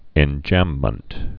(ĕn-jămmənt, -jămb)